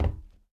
tbd-station-14/Resources/Audio/Effects/Footsteps/plating5.ogg